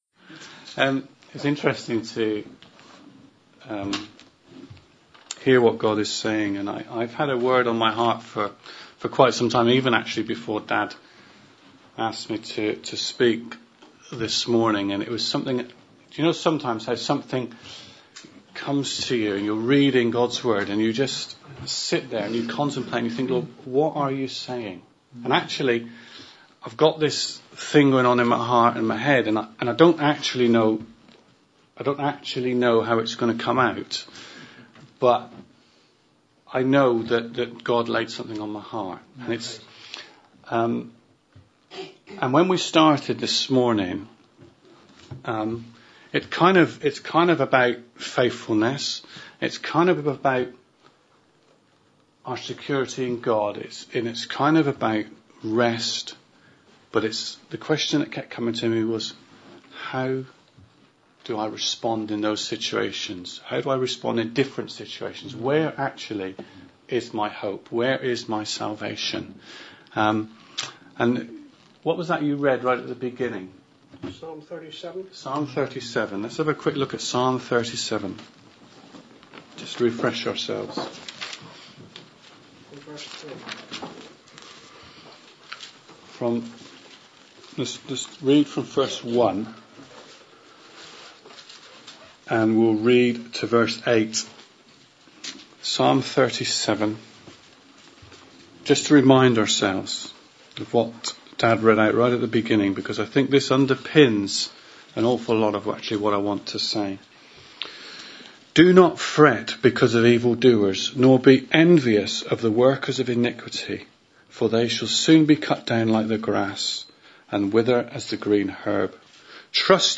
Preached at Rora Christian Fellowship Devon UK